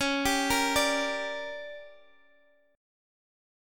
Listen to Gm/Db strummed